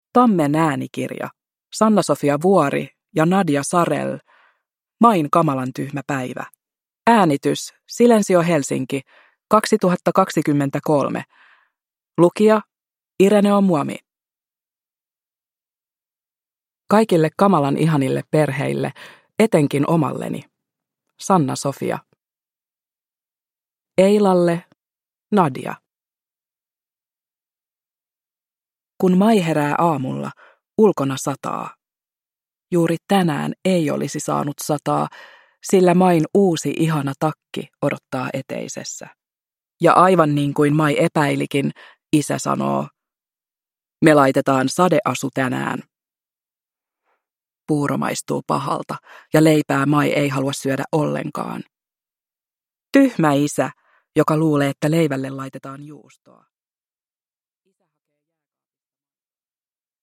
Main kamalan tyhmä päivä – Ljudbok – Laddas ner